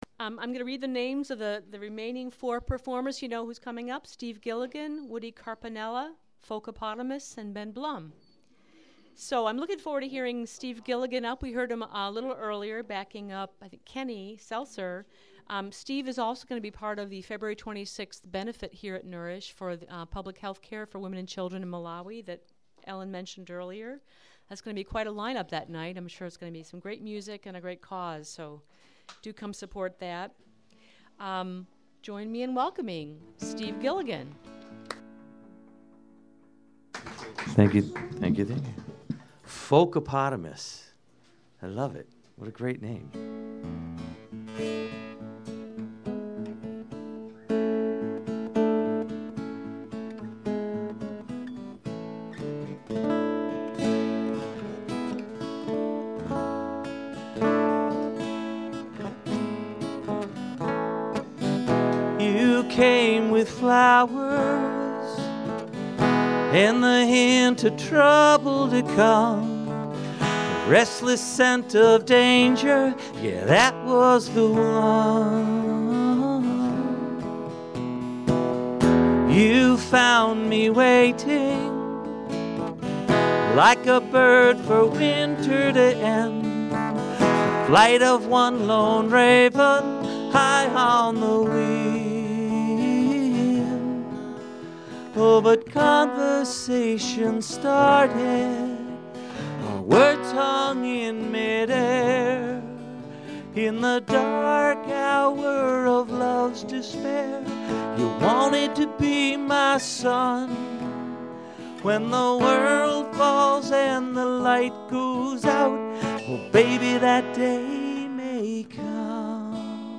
Files with a title starting raw or Untitled have only been track-level volume adjusted and are not joined, clipped, equalized nor edited.
raw Nourish Restaurant Open Mike, 1/29/13